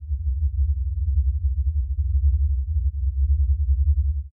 ambient_drone_4.ogg